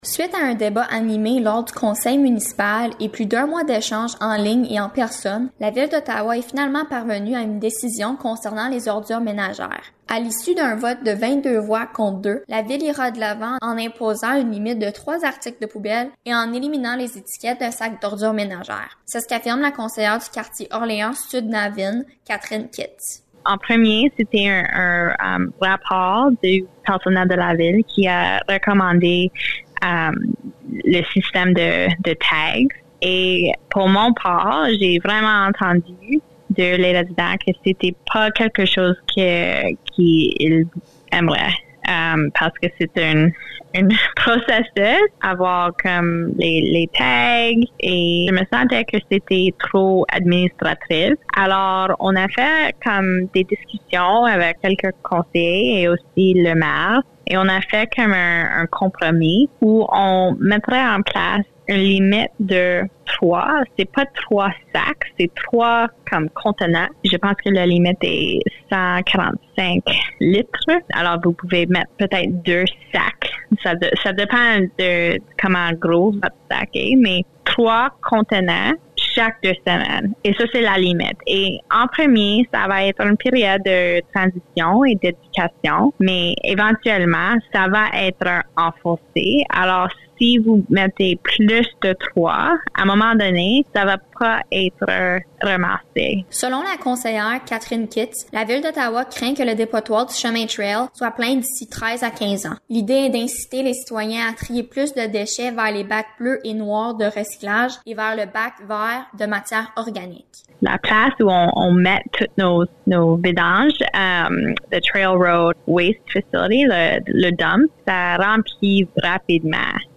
Reportage-ordure-menagere-30-juin.mp3